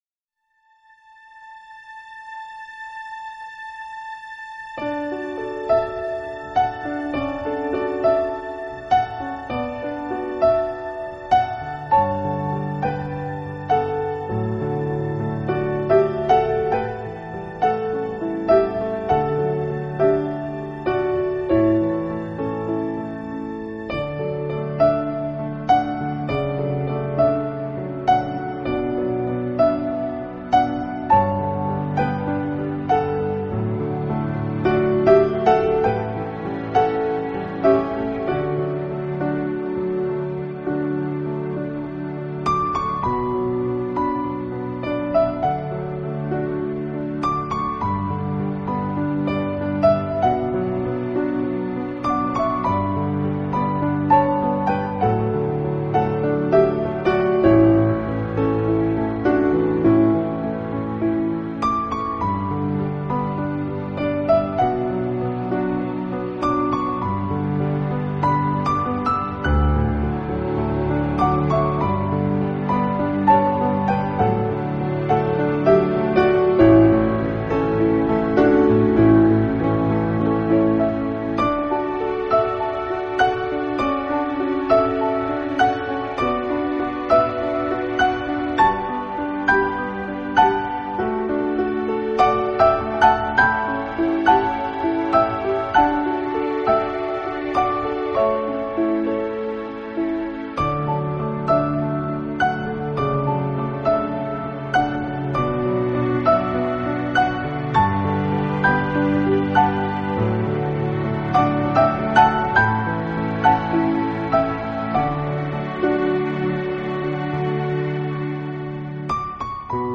简单的吉他的轻奏揉和入自然的画面，给恬静的氛围添加一丝的铨释和画笔！